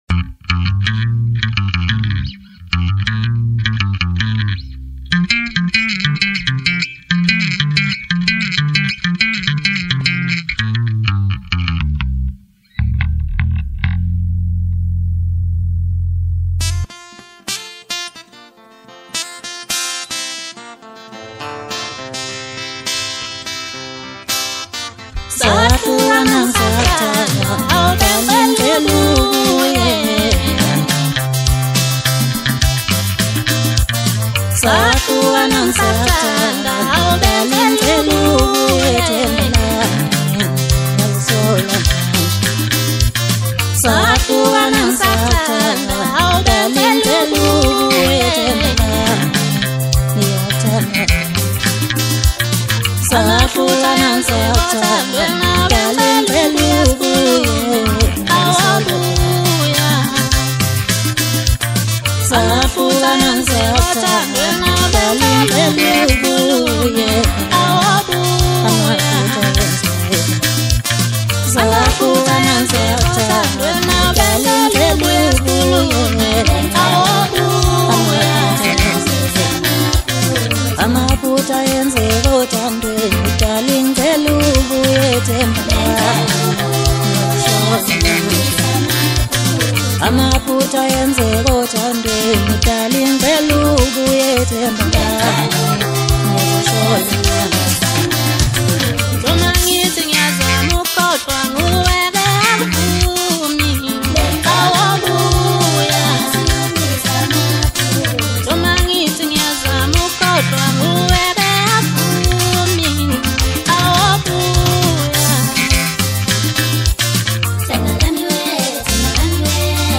catchy rhythm, smooth vibe